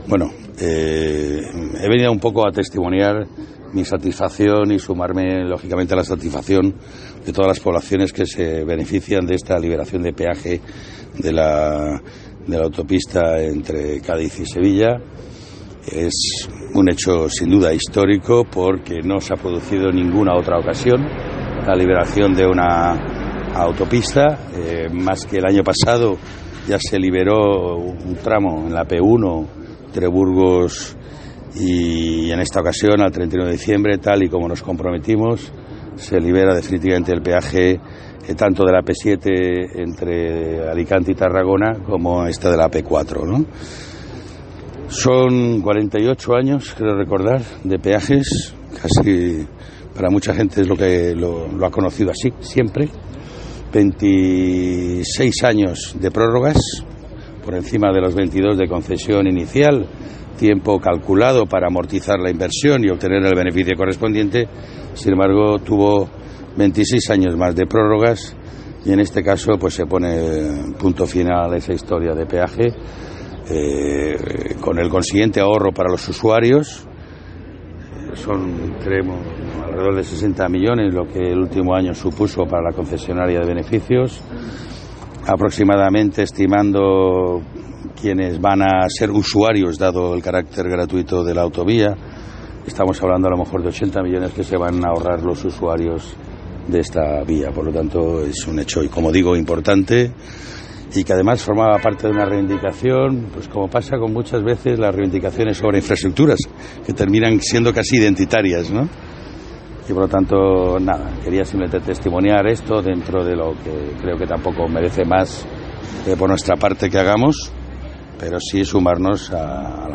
José Luis Ábalos, ministro de Fomento en funciones, sobre el peaje entre Cádiz y Sevilla